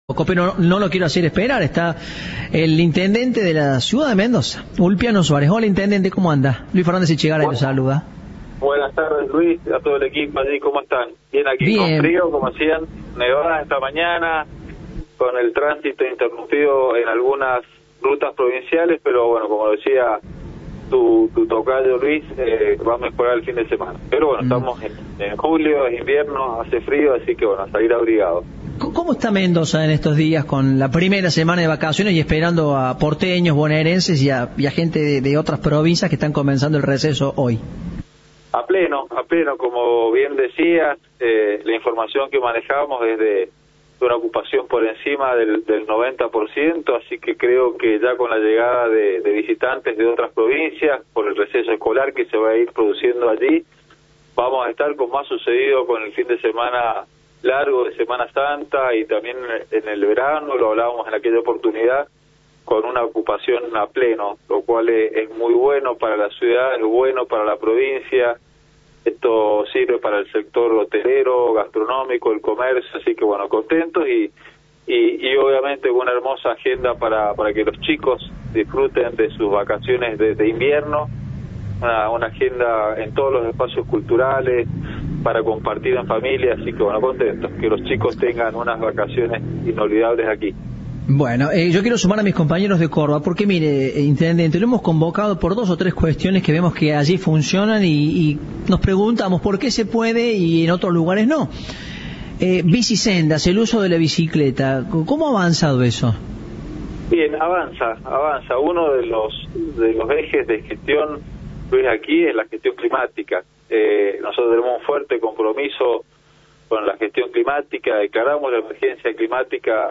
El intendente de Mendoza contó a Cadena 3 las principales propuestas de su gestión ante la emergencia climática. Promovió el desarrollo económico, la integración social y el cuidado del ambiente.